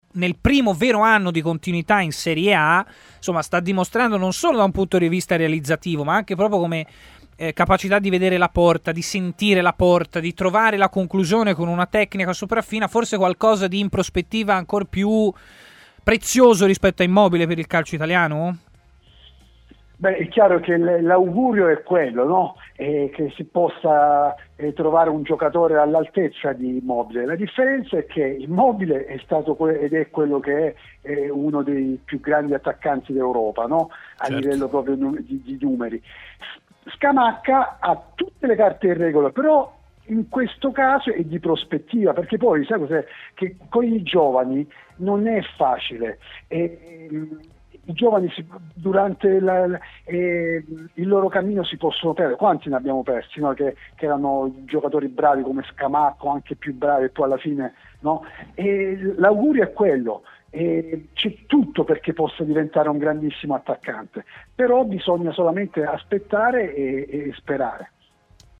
Intervenuto ai microfoni di TMW Radio